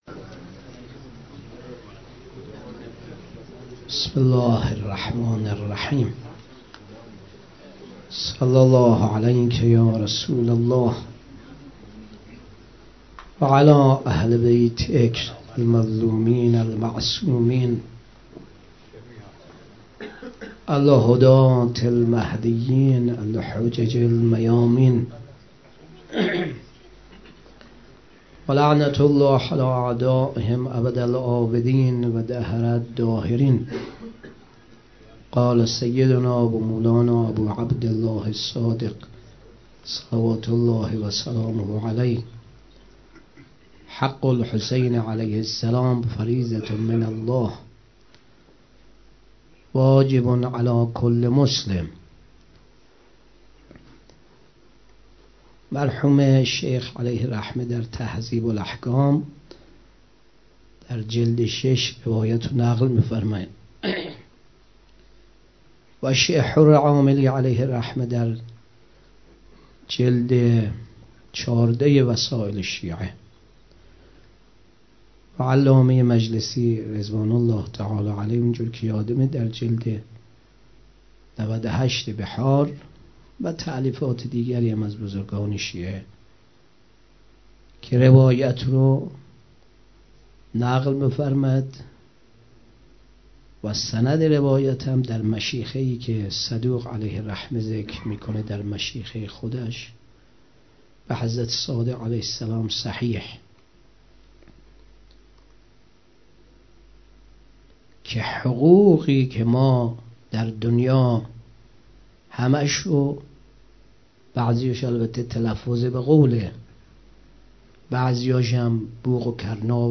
ولادت انوار کربلا 96 - غمخانه بی بی شهربانو - سخنرانی